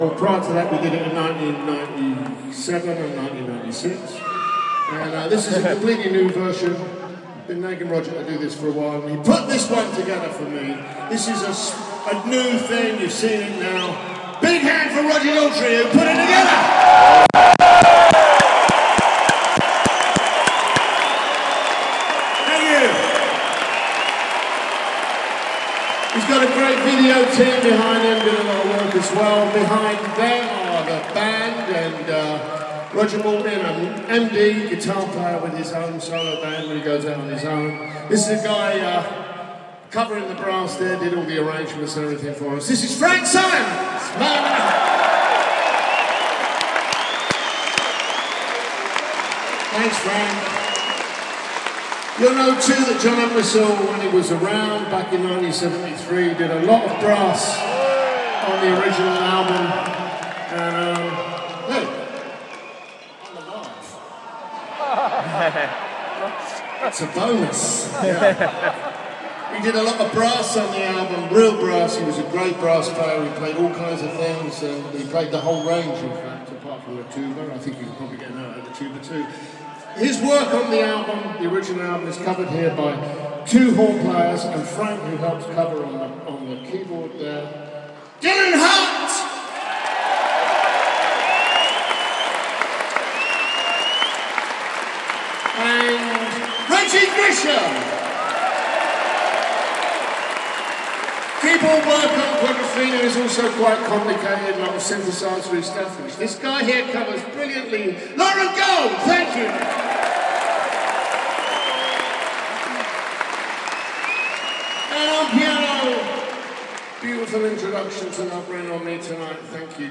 Pete Townsend addresses the O2 audience